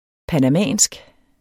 panamansk adjektiv Bøjning -, -e Udtale [ panaˈmæˀnsg ] Betydninger fra det mellemamerikanske land Panama; vedr. Panama eller panamanerne